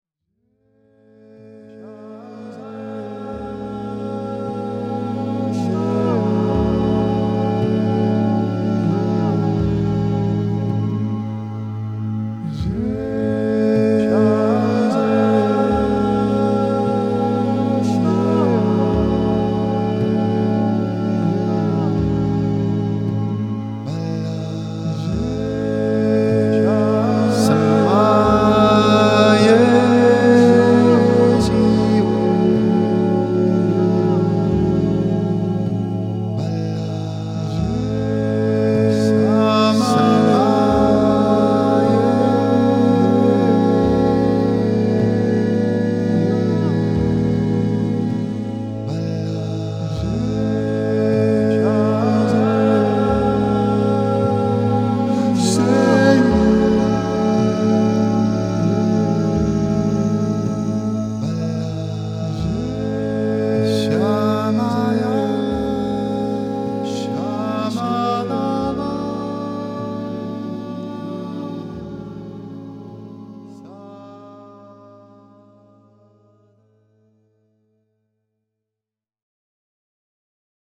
I don't think of any specific words when I sing.